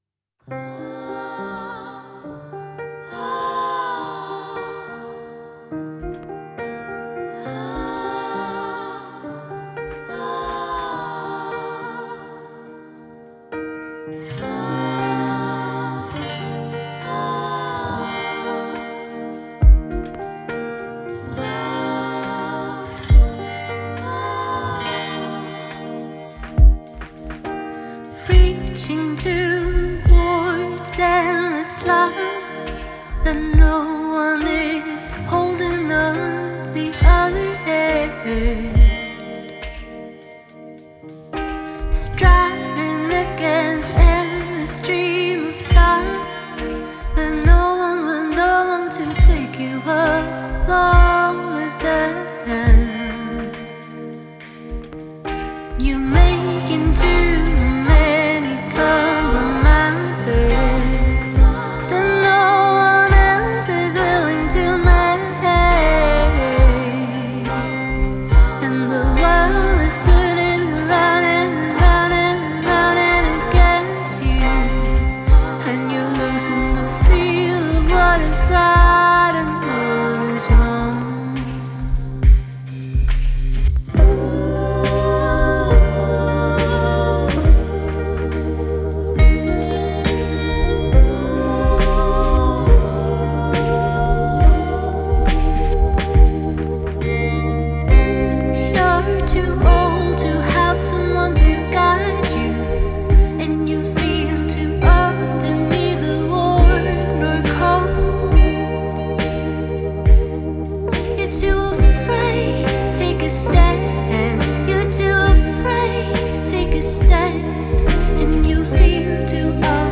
Genre: Indie, Rock, Pop